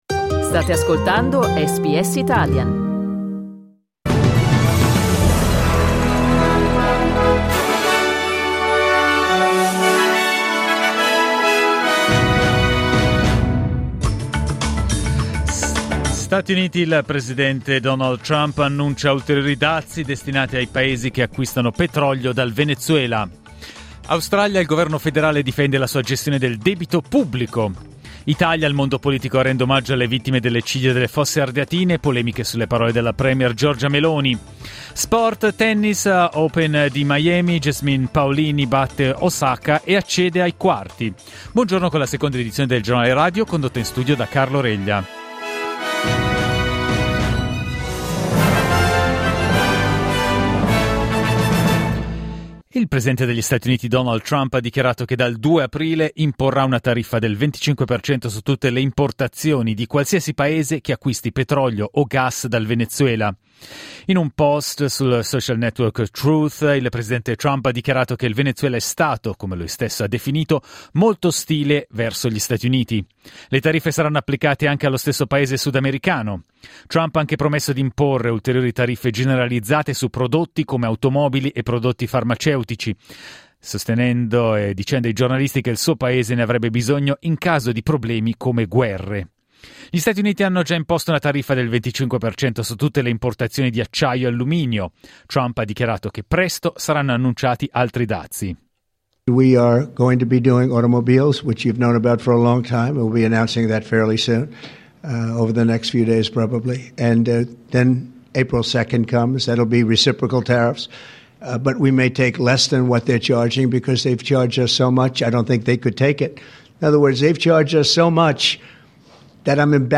Giornale radio martedì 25 marzo 2025
Il notiziario di SBS in italiano.